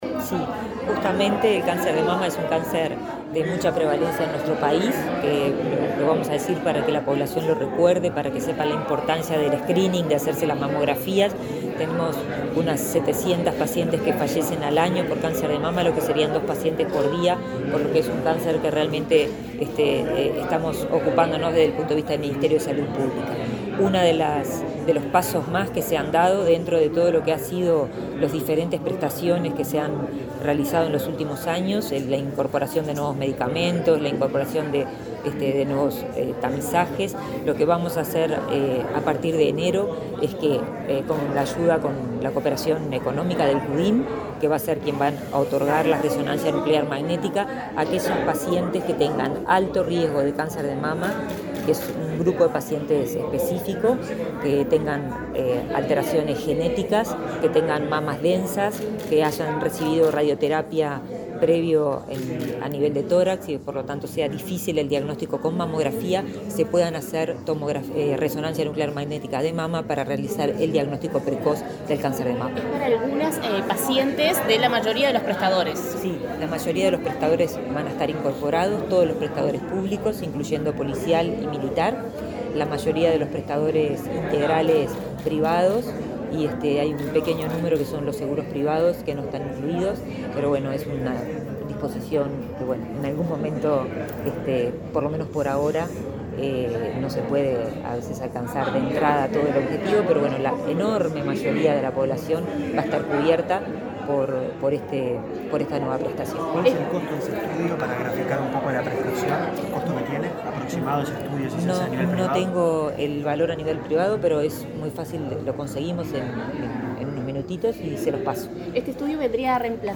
Declaraciones de la ministra de Salud Pública, Karina Rando
Este jueves 19, la ministra de Salud Pública, Karina Rando, dialogó con la prensa, luego de participar en el acto por el Día Mundial contra el Cáncer